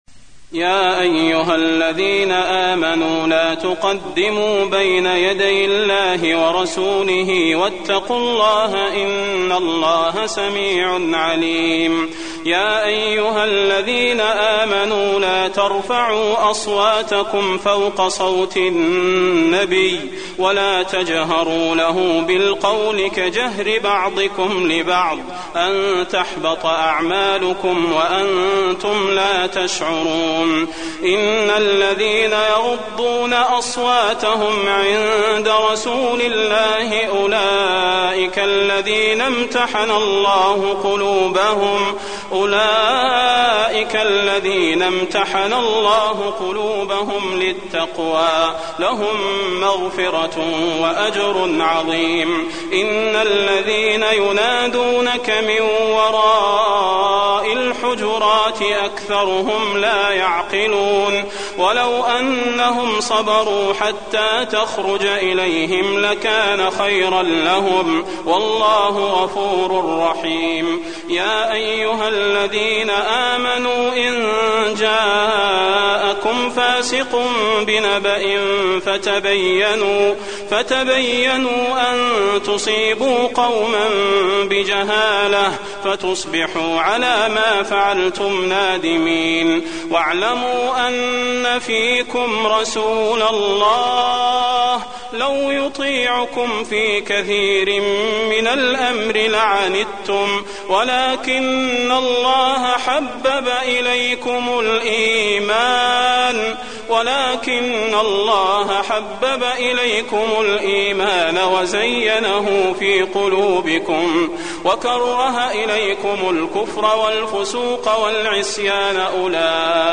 المكان: المسجد النبوي الحجرات